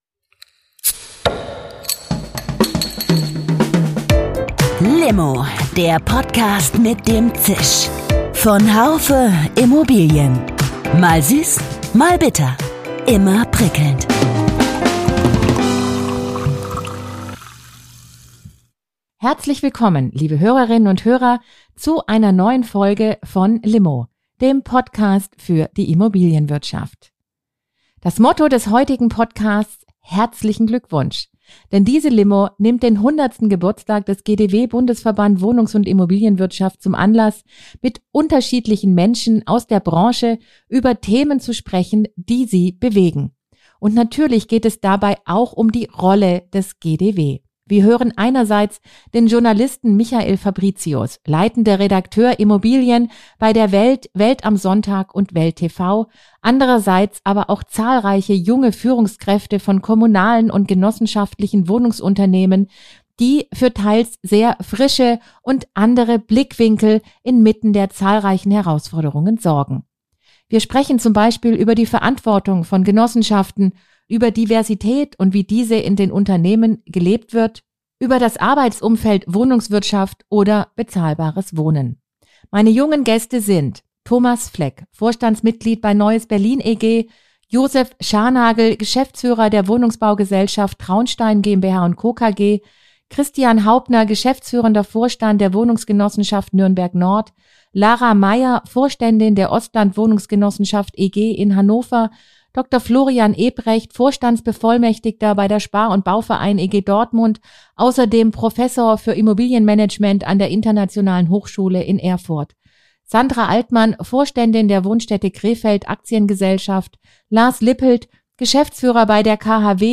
Auf einer Geburtstagsparty darf natürlich der Jubilar selbst nicht fehlen: Im GdW-Interview geht es vor allem darum, wie es einem Hundertjährigen gelingt, jung und frisch zu bleiben und auch genauso wahrgenommen zu werden.